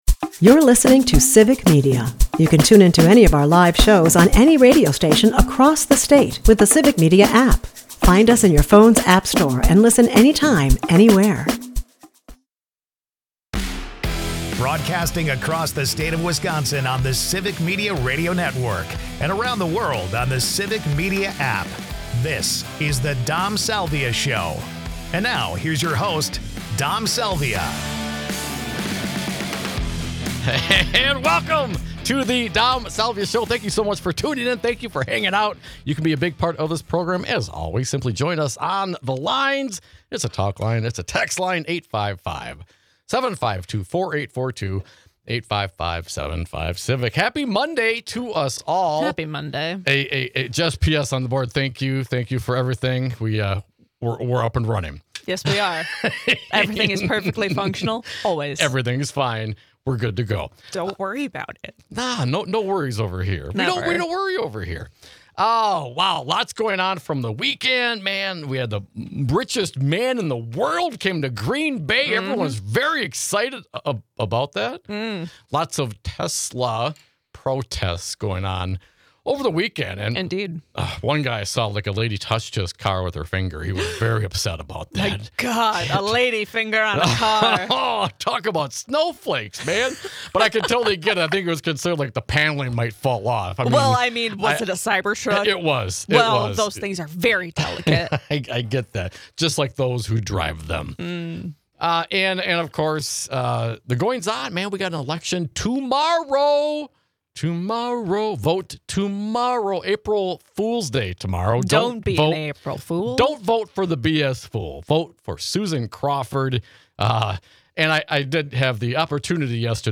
With this election shaping up to blow far past the record spending, we hear from our audience consternation over all the money in this race.